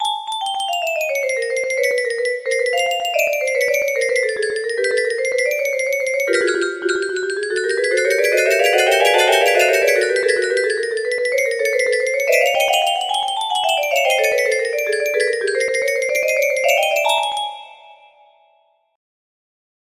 barett music box melody